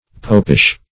Popish \Pop"ish\, a.